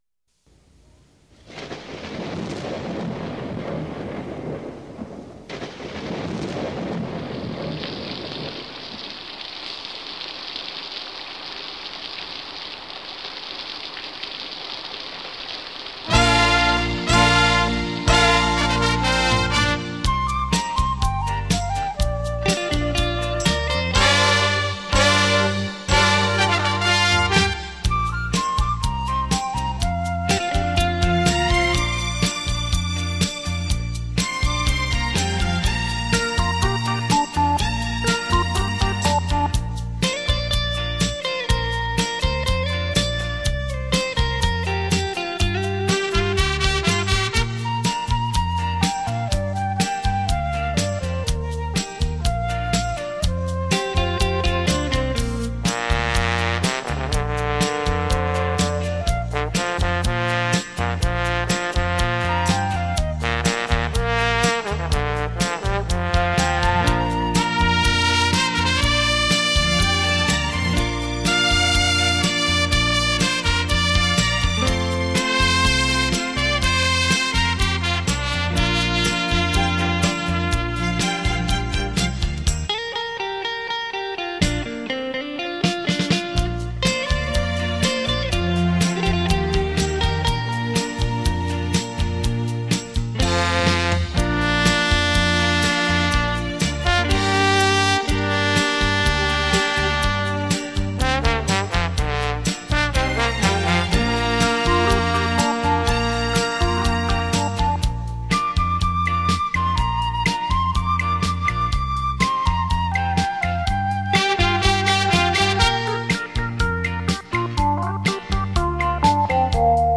錄音：佳聲錄音室
請您測耳聆聽，閒適優雅的音符，